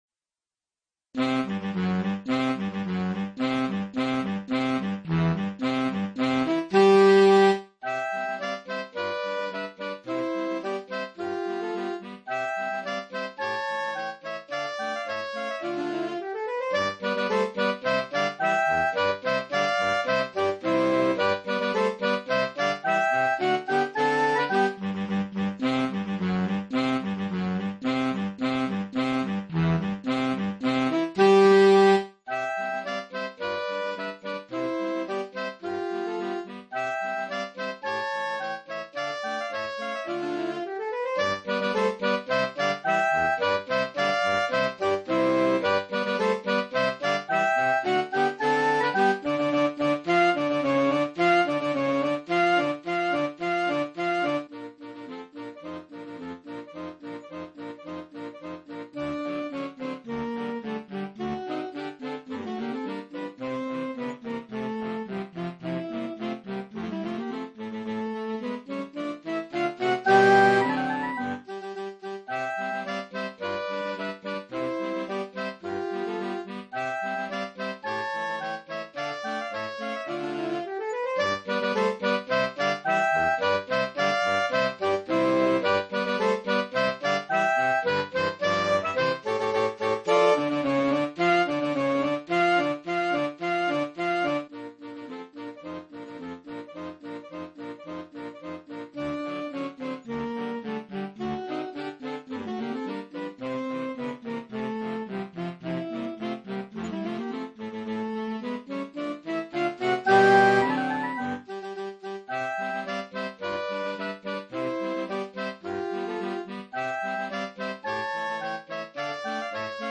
Voicing: Saxophone 4tet